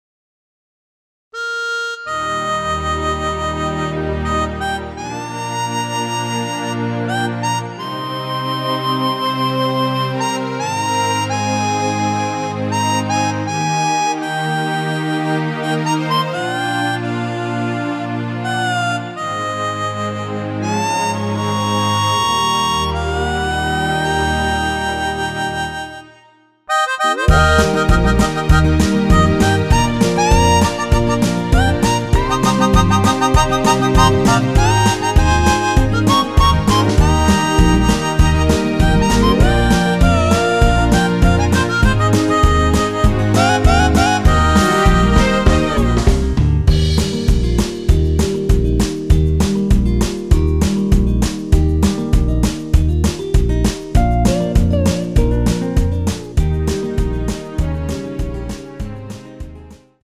원키에서(+3)올린 MR입니다.(미리듣기 확인)
Eb
앞부분30초, 뒷부분30초씩 편집해서 올려 드리고 있습니다.
중간에 음이 끈어지고 다시 나오는 이유는